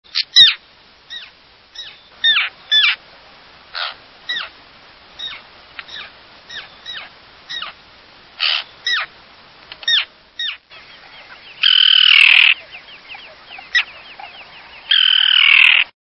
Common Tern
Common_Tern.mp3